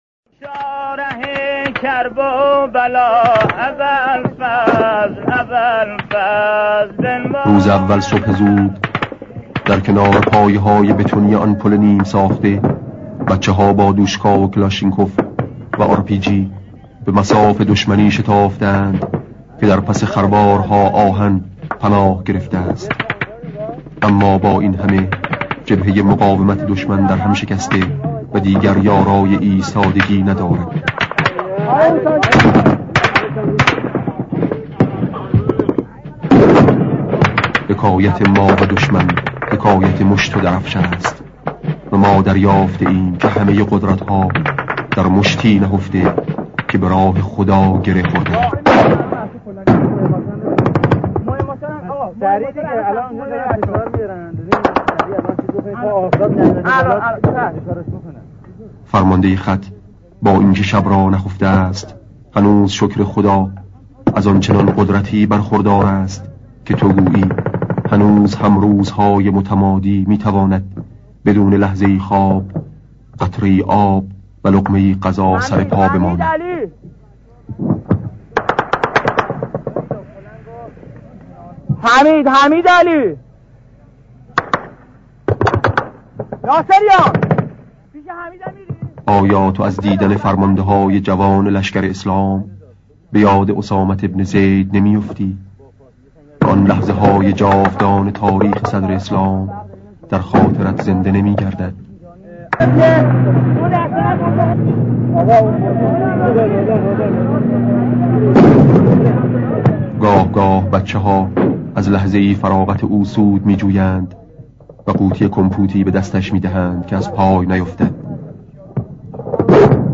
صدای شهید آوینی/ جبهه مقاومت دشمن در هم شکسته و دیگر یارای ایستادن ندارد.